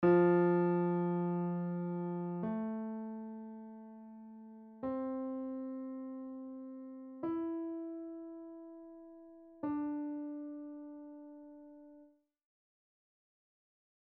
Look at the letters and find the notes on your piano; play the words
Piano Notes